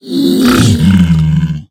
Minecraft Version Minecraft Version snapshot Latest Release | Latest Snapshot snapshot / assets / minecraft / sounds / mob / piglin / converted2.ogg Compare With Compare With Latest Release | Latest Snapshot